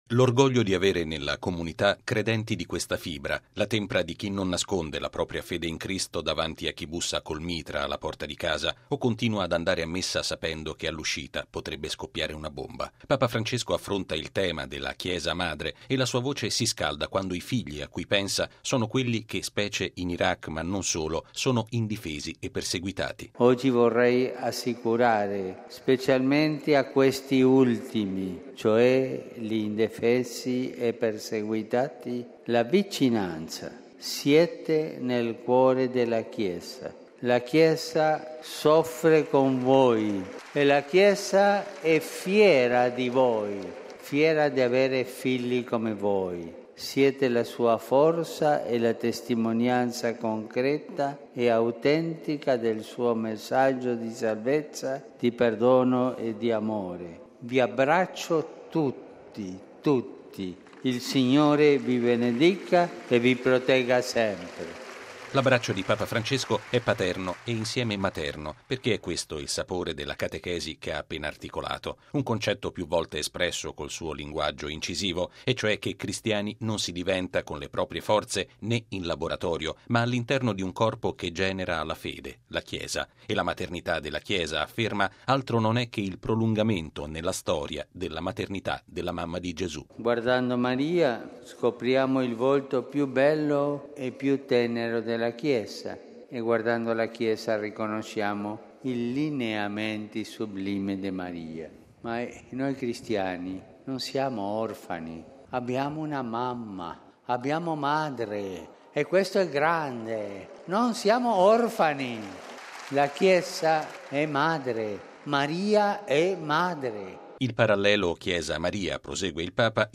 Lo ha affermato Papa Francesco all’udienza generale di stamattina in Piazza San Pietro, rivolgendosi ai cristiani perseguitati, specie in Iraq.